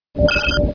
boot.wav